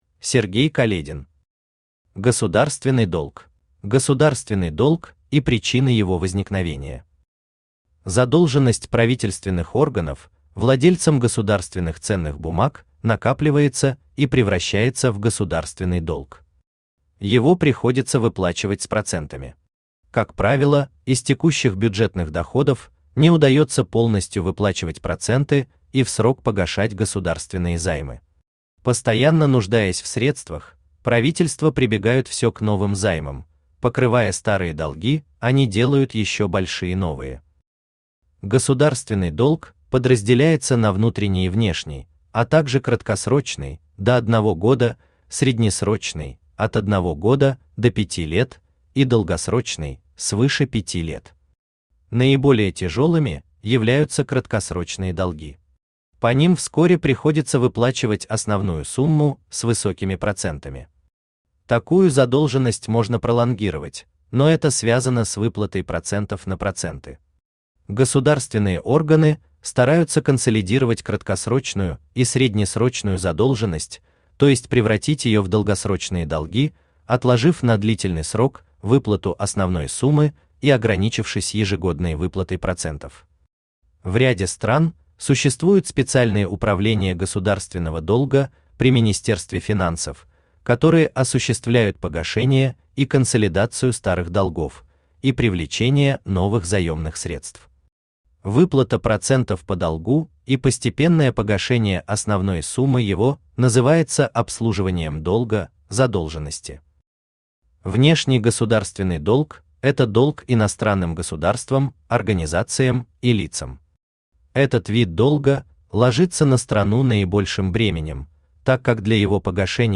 Аудиокнига Государственный долг | Библиотека аудиокниг
Aудиокнига Государственный долг Автор Сергей Каледин Читает аудиокнигу Авточтец ЛитРес.